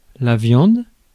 Ääntäminen
IPA: [vjɑ̃d]